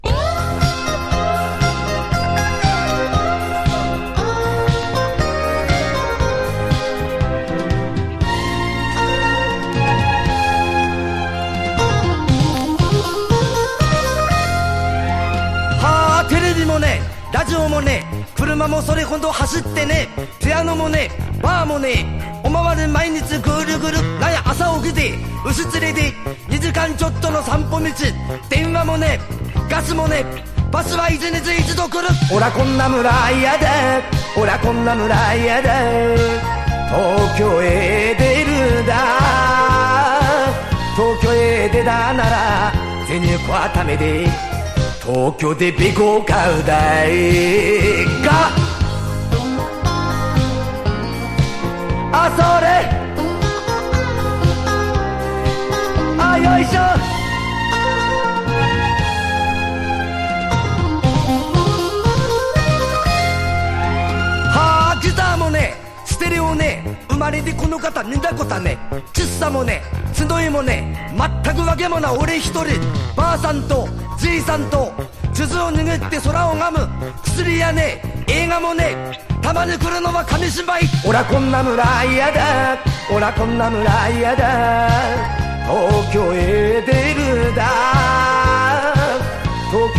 日本語ラップのパイオニア曲
POP